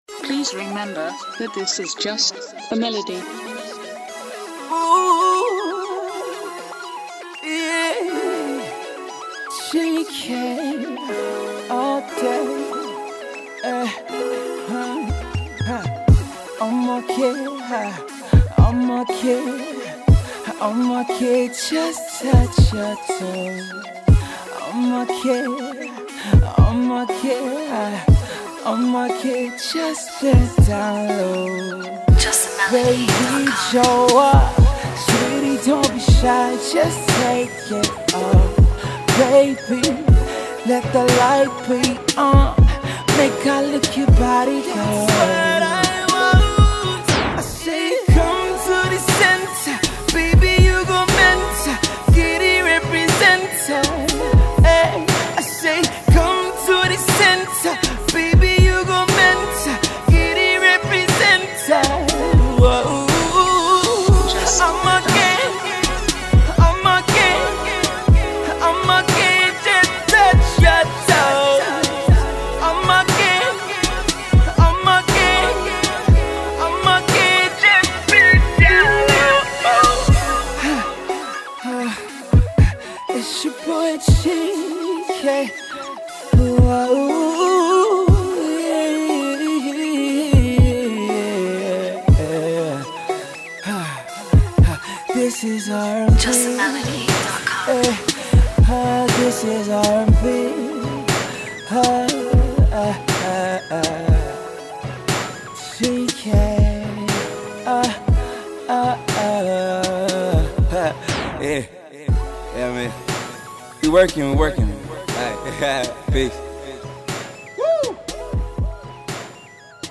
R&B tune